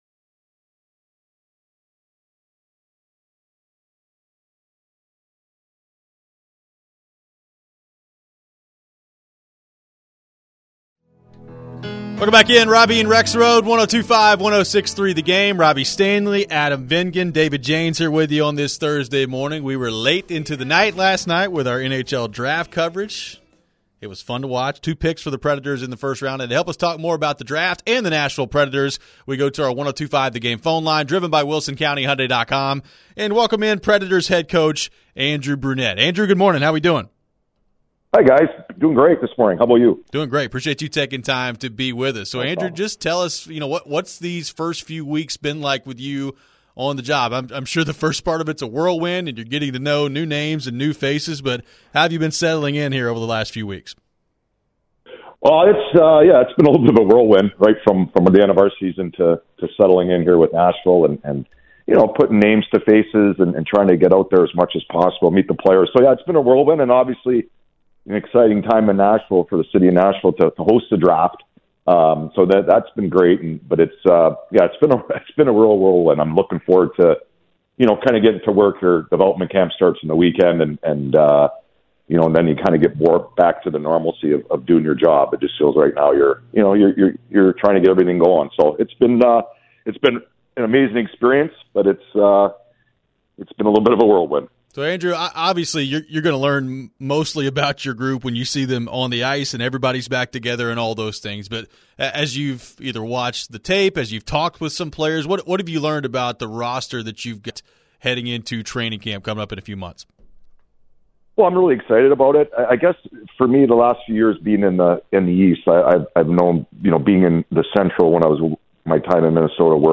Andrew Brunette Interview (6-29-23)